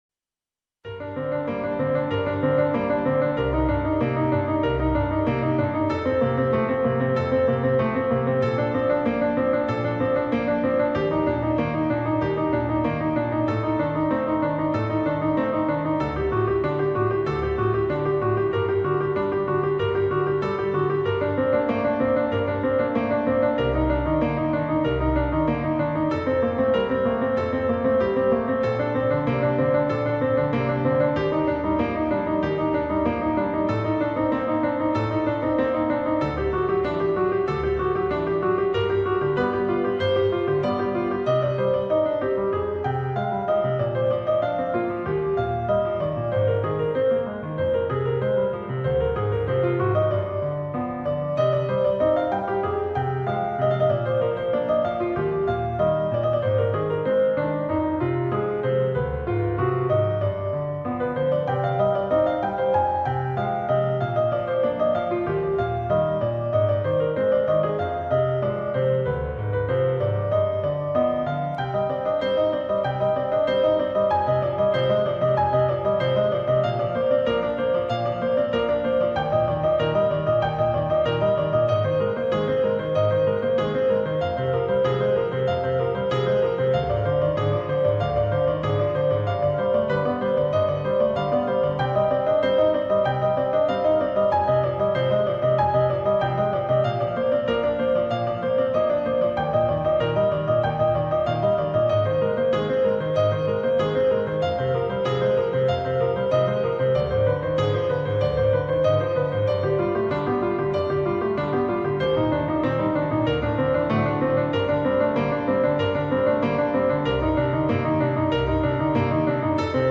/ فیلم برچسب‌ها: باخ موسیقی کلاسیک دیدگاه‌ها (3 دیدگاه) برای ارسال دیدگاه وارد شوید.